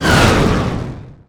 The medium-strength "blow away" sound effect as heard in Super Smash Bros. Utimate, a video game published by Nintendo.